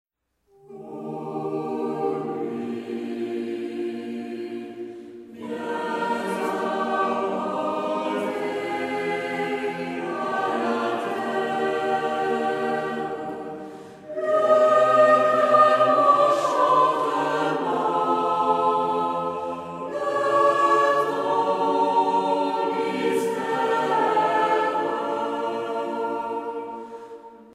circonstance : Noël, Nativité
Pièce musicale éditée